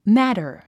発音
mǽtər　マァタァ